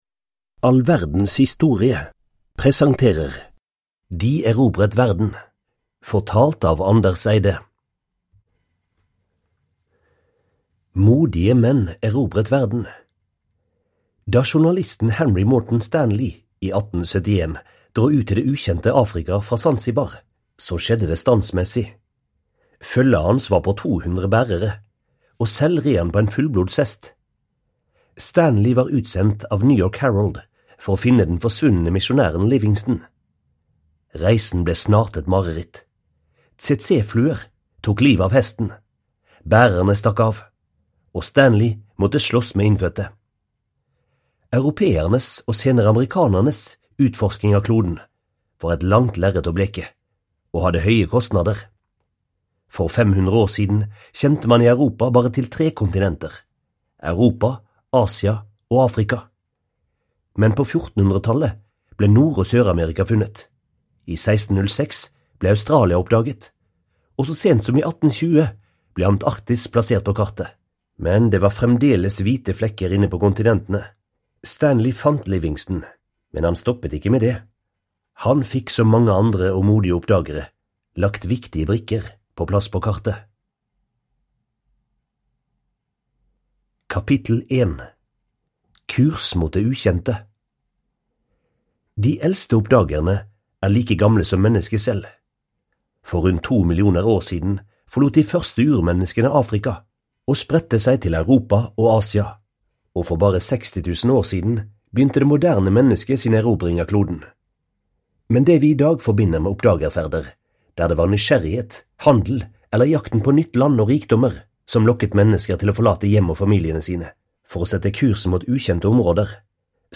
De erobret verden (ljudbok) av All verdens historie